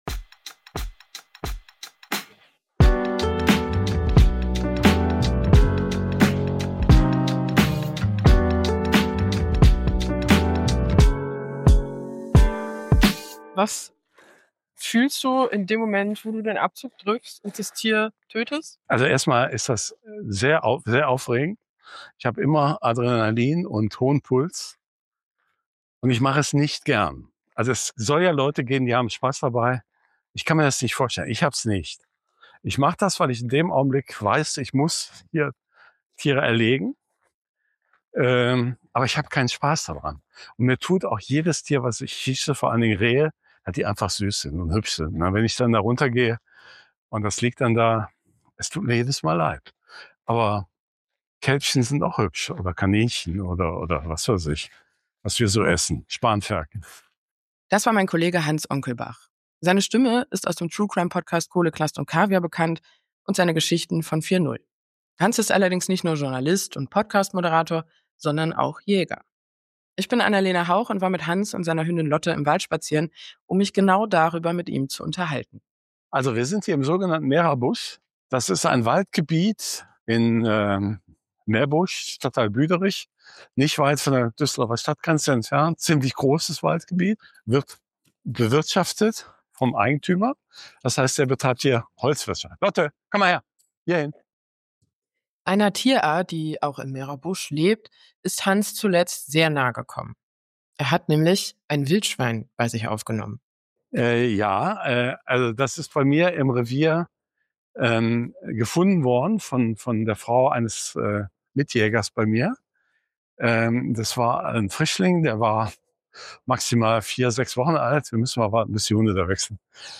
in einem Wald bei Düsseldorf unterwegs. Er ist Jäger, sie ist Vegetarierin.